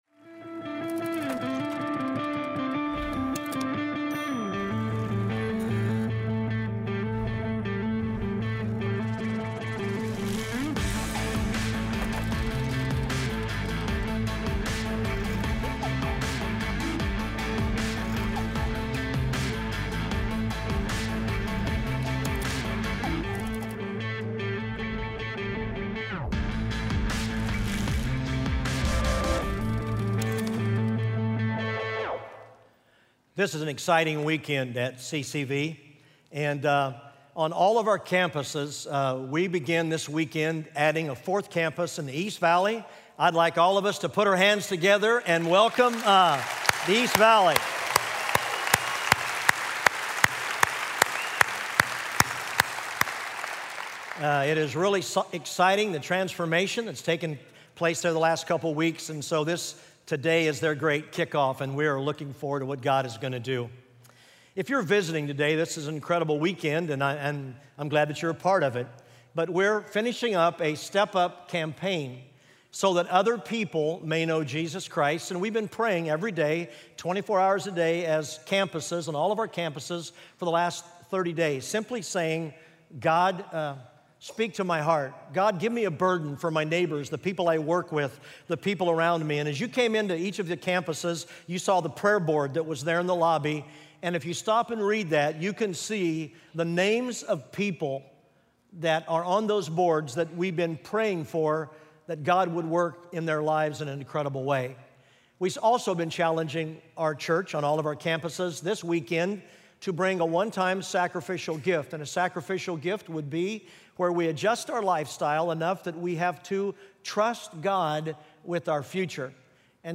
Step Up: So That Others May Know: Impact (Full Service)